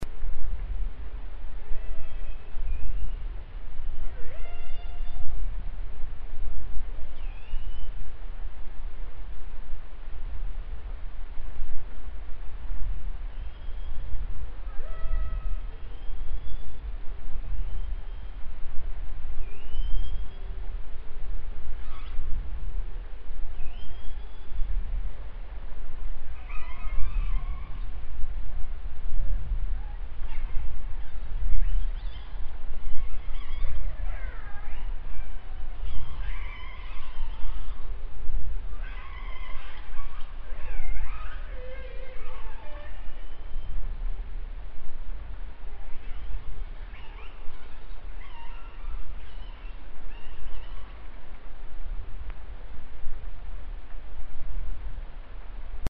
Researchers used underwater microphones to track the whales as they made their way through the strait towards the Chukchi Sea.
Killer Whales in the Bering Strait
killerwhalesOct2011.mp3